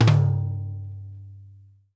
FLAMFLOOR2-L.wav